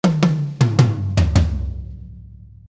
Die Toms trocken: